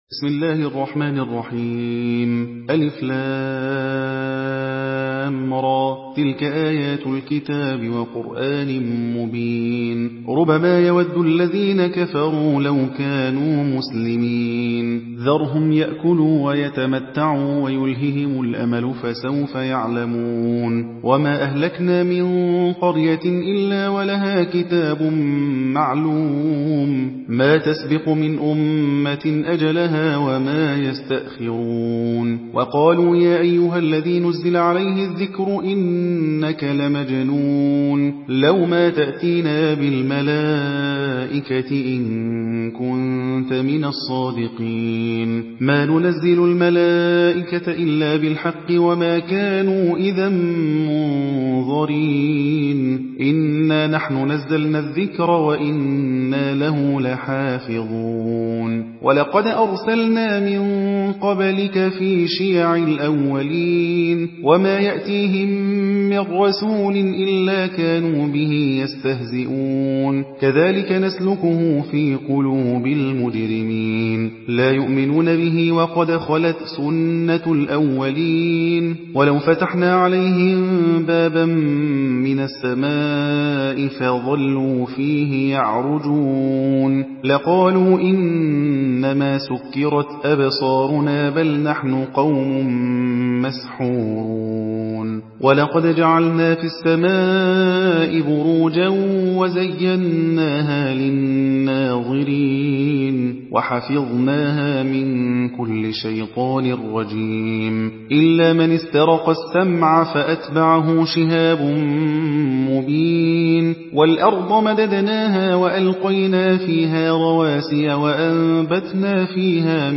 Hafs An Asim